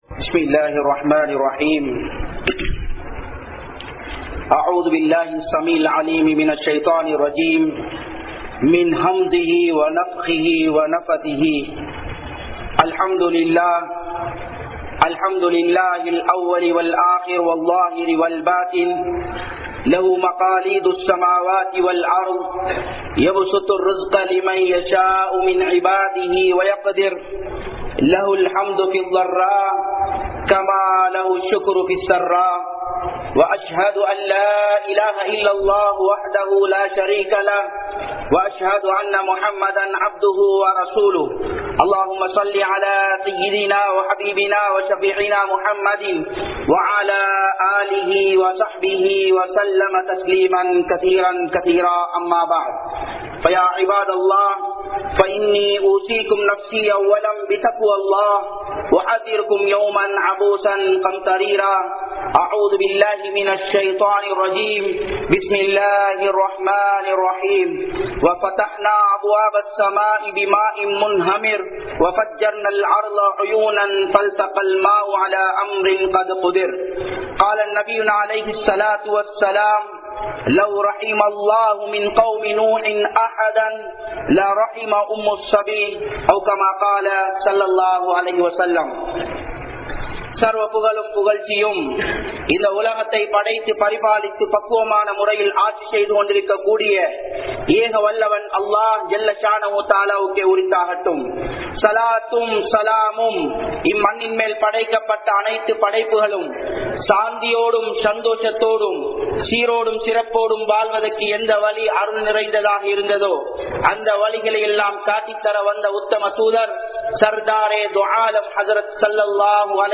Looth(Alai)Avarhalin Samoohaththitku Vantha Soathanaihal (லூத்(அலை)அவர்களின் சமூகத்திற்கு வந்த சோதனைகள்) | Audio Bayans | All Ceylon Muslim Youth Community | Addalaichenai